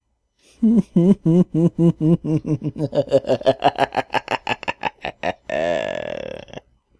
Evil Laugh 3
cocky crazy demented evil insane laugh laughing laughter sound effect free sound royalty free Funny